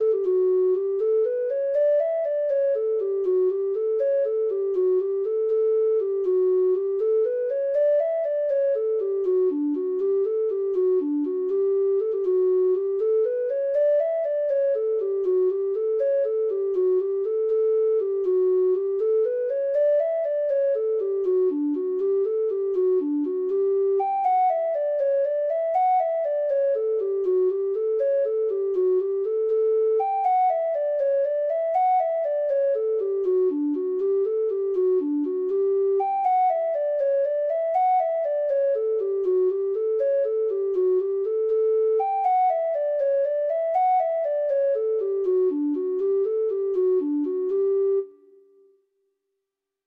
Traditional Trad. Bessy Murphy (Irish Folk Song) (Ireland) Treble Clef Instrument version
Traditional Music of unknown author.
Irish